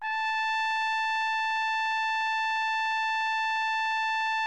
TRUMPET   28.wav